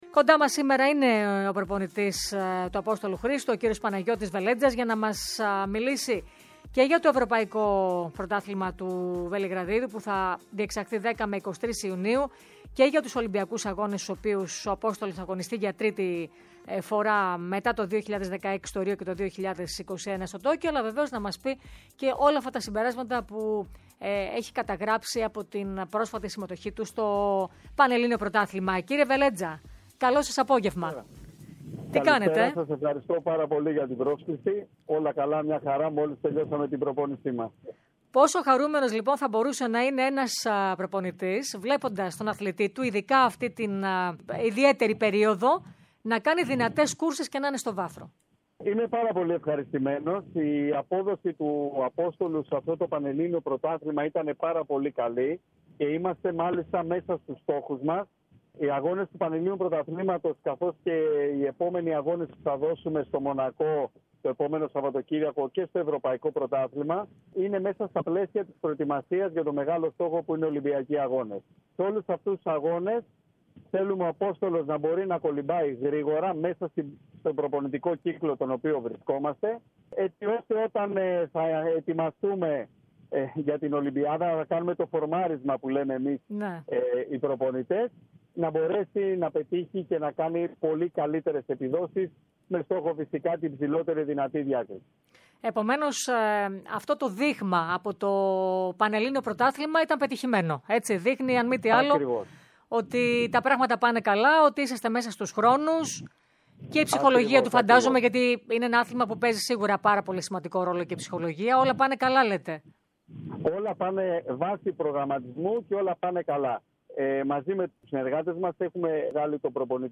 μιλώντας στην ΕΡΑΣΠΟΡ και στην εκπομπή CITIUS ALTIUS FORTIUS